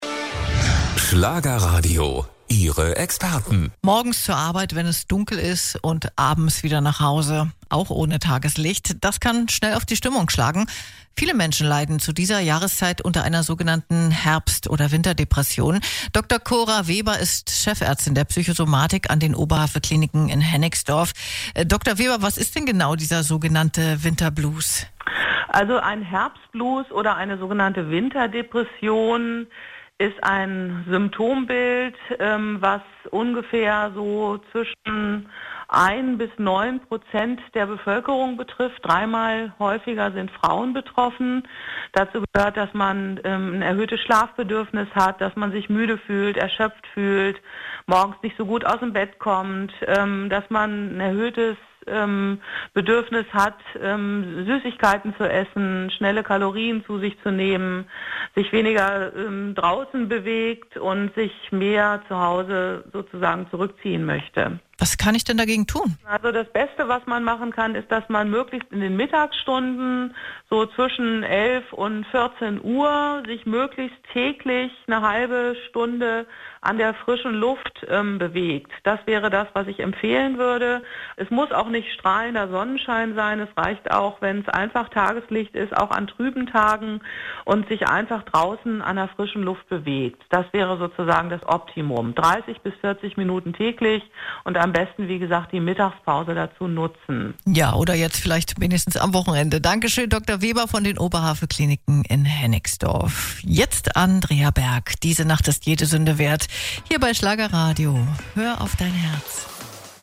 im Interview bei Schlagerradio.